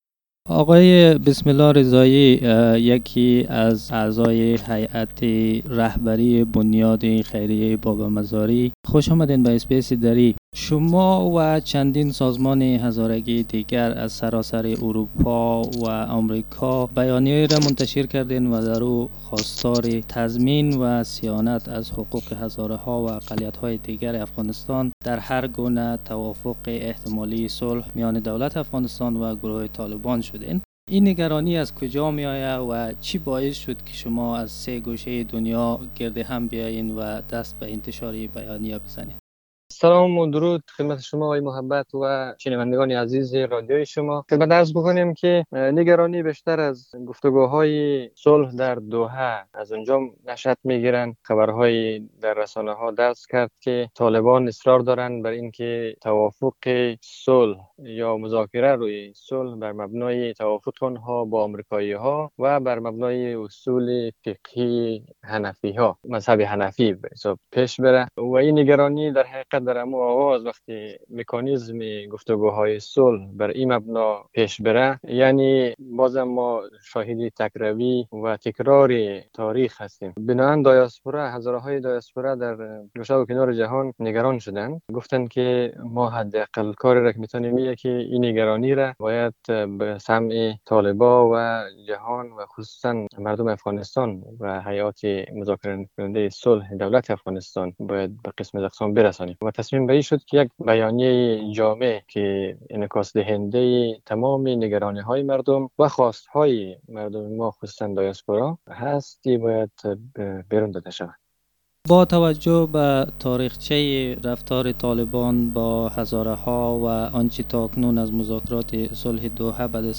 The interview is in the Dari language